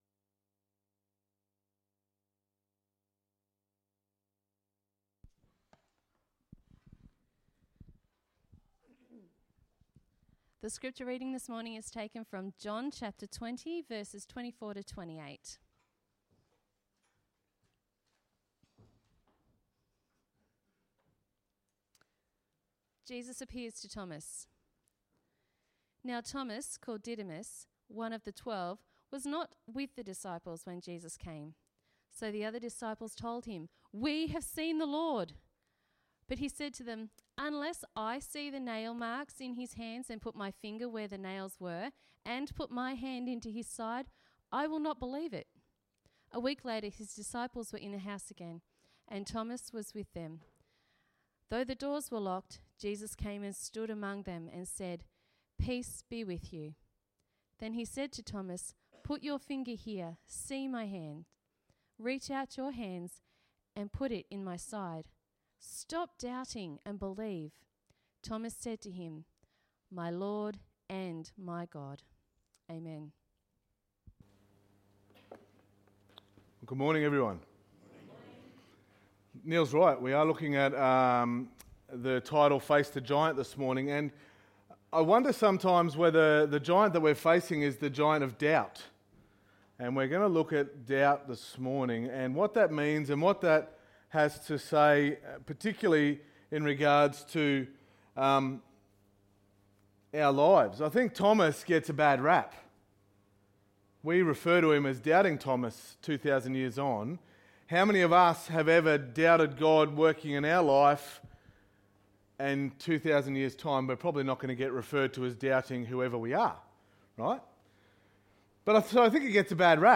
Sermon 25.08.19